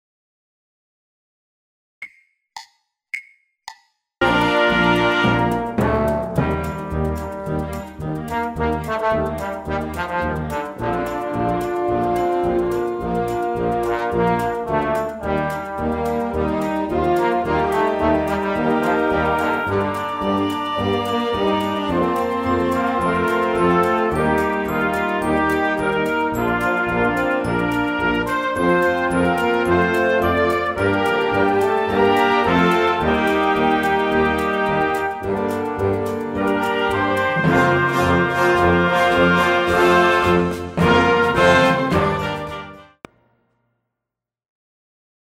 Originaltempo 108 bpm,    96 bpm